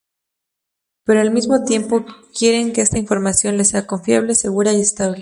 con‧fia‧ble
/konˈfjable/